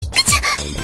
Play D-dekomori Sneezu - SoundBoardGuy